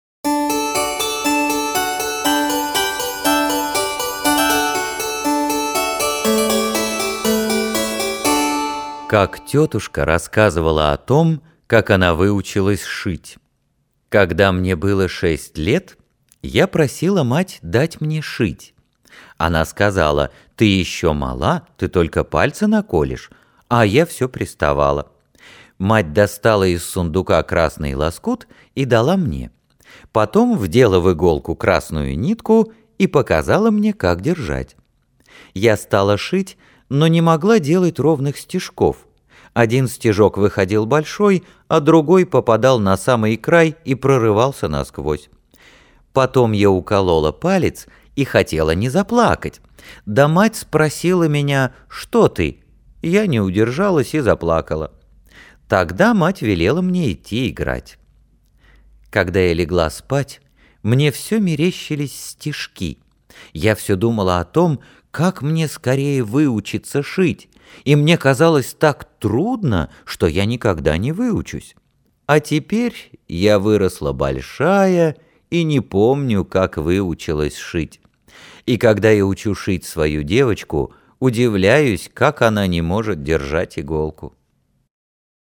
Как тетушка рассказывала о том, как она выучилась шить - аудиокнига Л.Н. Толстого